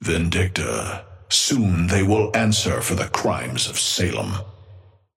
Amber Hand voice line - Vindicta, soon they will answer for the crimes of Salem.
Patron_male_ally_hornet_start_04.mp3